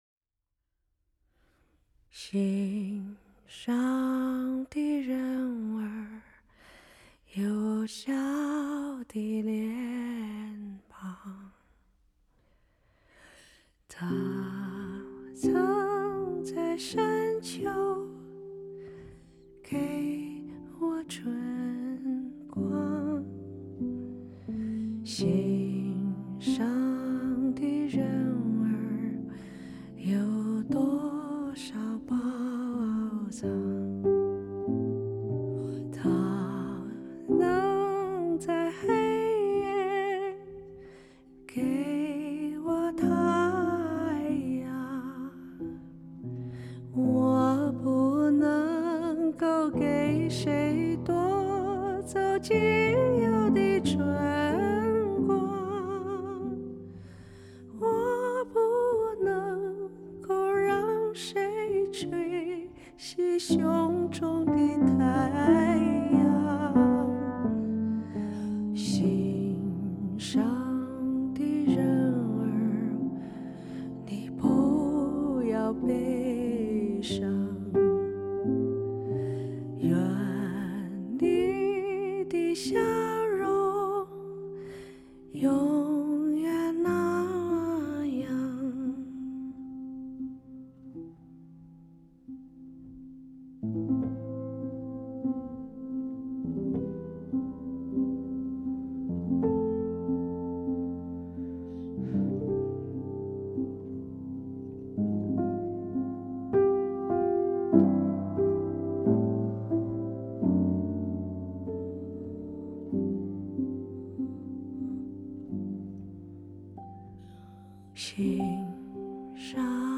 电影片尾曲
人声
钢琴